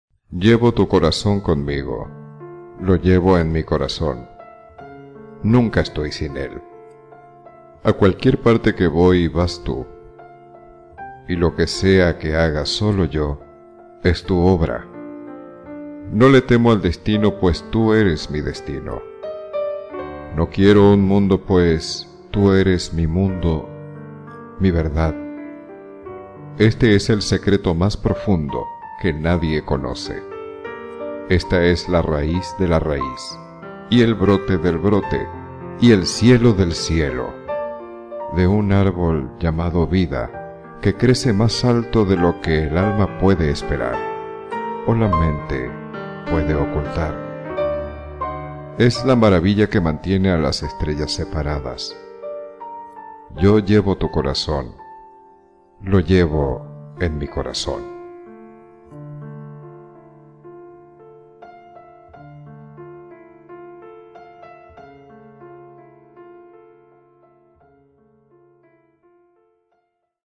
Suave, profunda y sugestiva